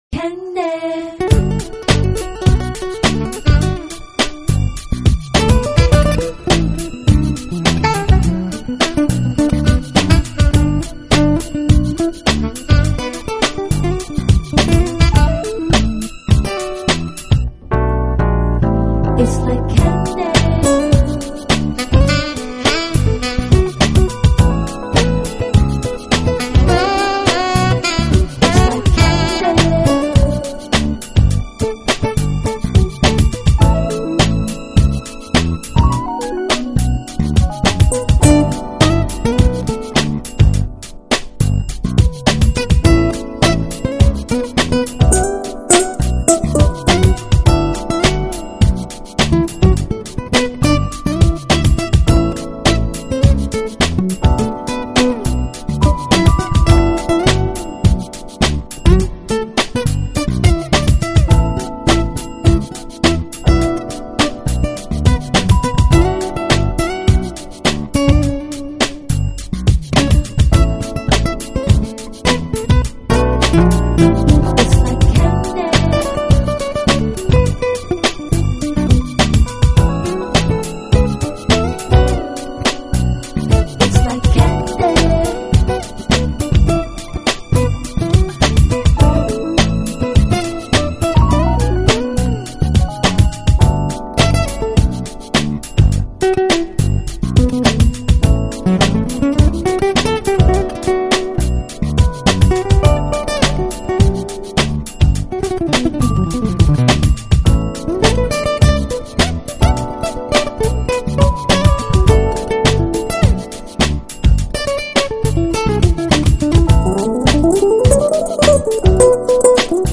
Genre:Hip-Hop/Smooth Jazz